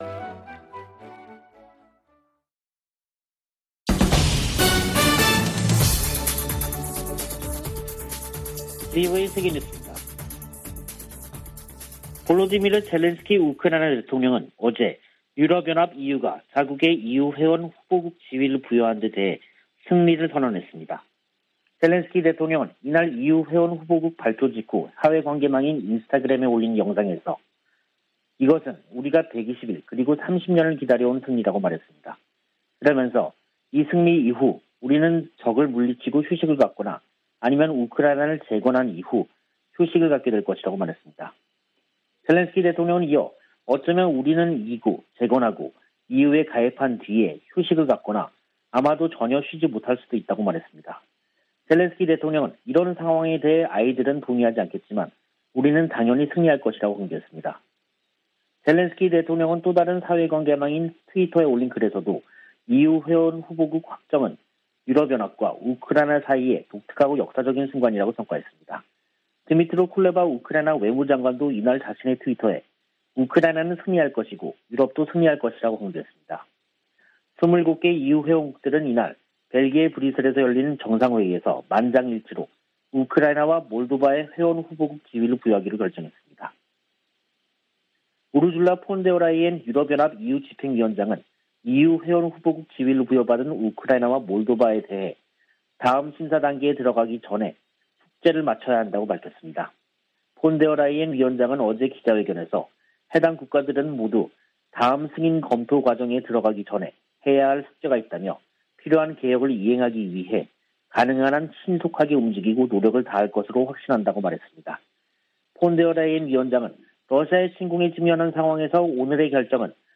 VOA 한국어 간판 뉴스 프로그램 '뉴스 투데이', 2022년 6월 24일 3부 방송입니다. 미 하원 군사위 의결 국방수권법안(NDAA)에 한국에 대한 미국의 확장억제 실행 방안 구체화를 요구하는 수정안이 포함됐습니다. 미국의 군사 전문가들은 북한이 최전선에 전술핵을 배치한다고 해도 정치적 의미가 클 것이라고 지적했습니다. 북한은 노동당 중앙군사위원회 확대회의에서 전쟁억제력 강화를 위한 중대 문제를 심의 승인했다고 밝혔습니다.